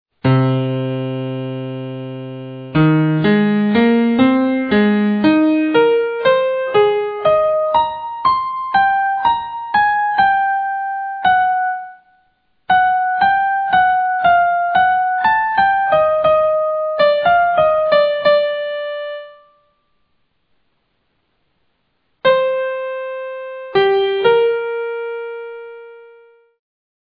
The very beginning of the ballade introduces the key of A flat major, until we encounter an F sharp.